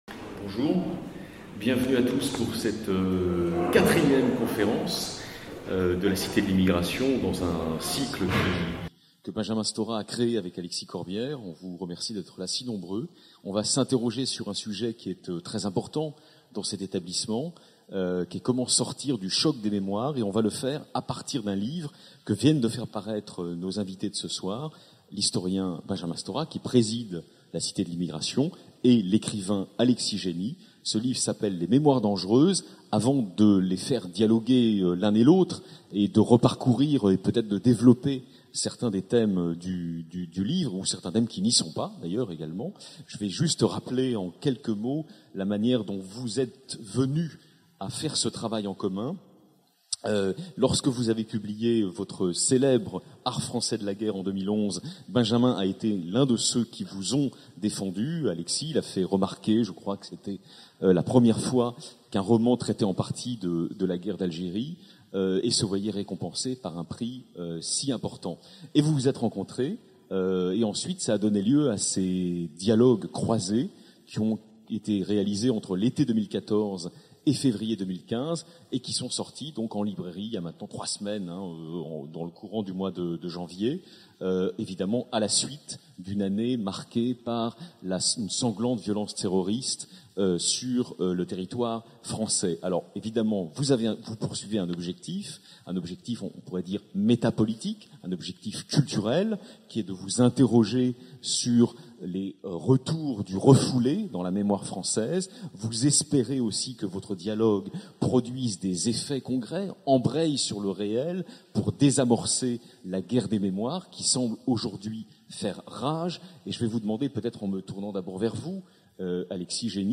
Sixième rencontre du cycle Quand l'immigration fait débat avec l'écrivain Alexis Jenni et l'historien Benjamin Stora, à l’occasion de la parution de leur ouvrage commun "Les mémoires dangereuses" (Éditions Albin Michel). Notre pays doit faire face aujourd'hui à une violence des mémoires.